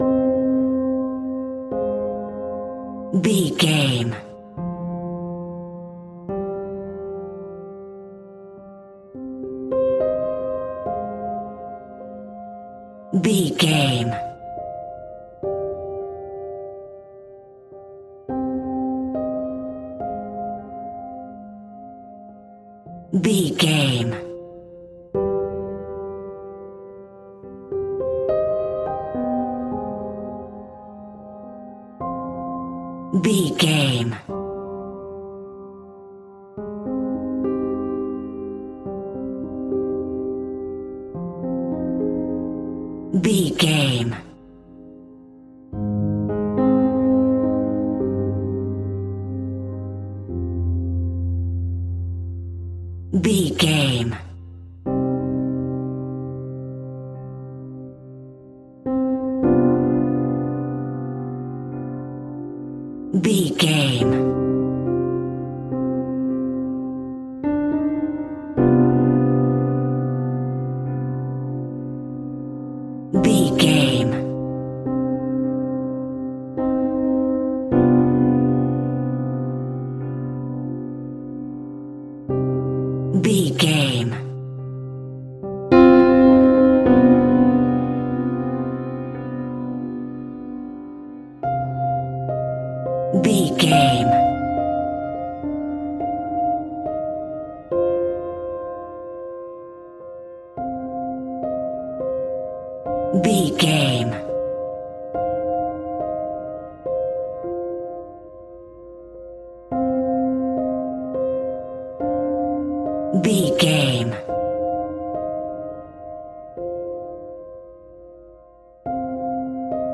Aeolian/Minor
ominous
eerie
horror music
Horror Pads
horror piano
Horror Synths